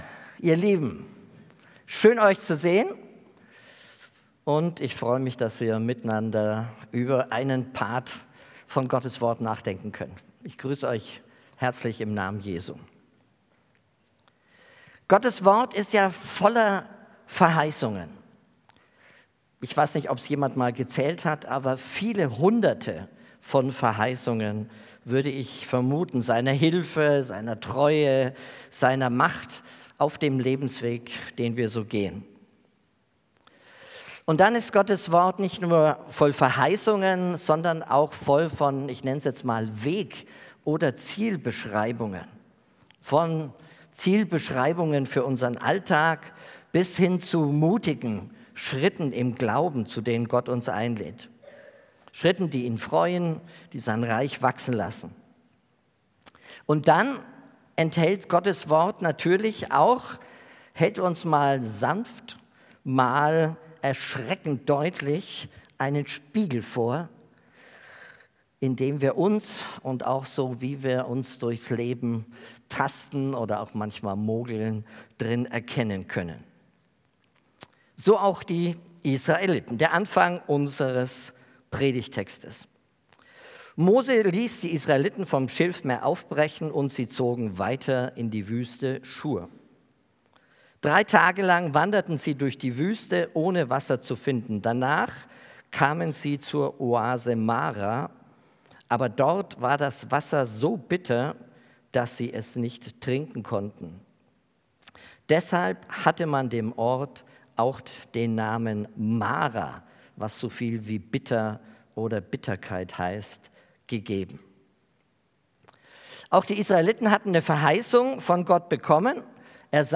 Predigt Durch die Wüste